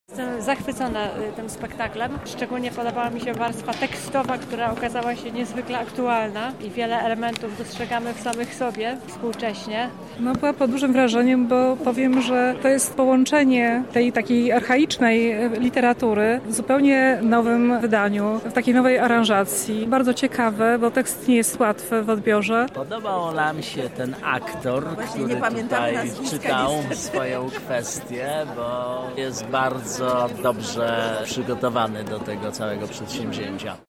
O wrażeniach z uczestnikami rozmawiała nasza reporterka.